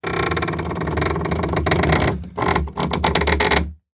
horror
Gate Wooden Gate Rattle Squeak 01